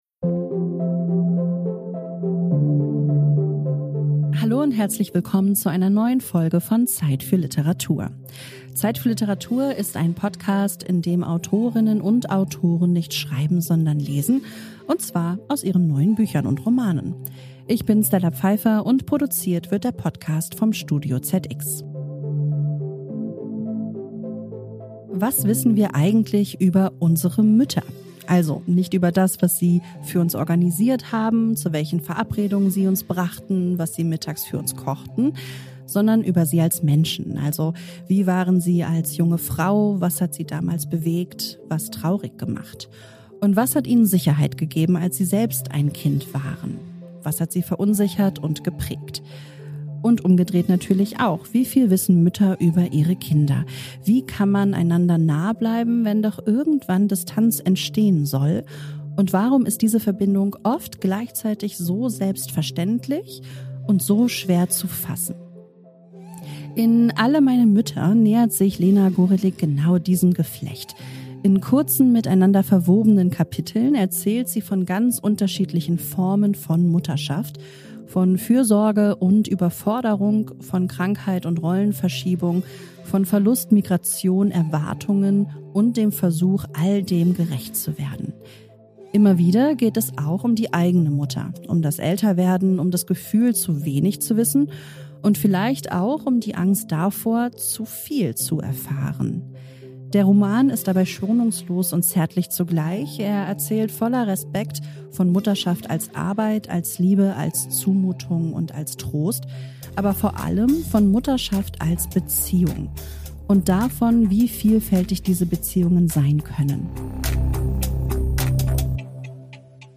In dieser Folge von ZEIT für Literatur liest Gorelik aus ihrem Roman und berichtet im Interview von den Müttern, die ihr beim Schreiben begegnet sind: erinnerte, erfundene, beobachtete.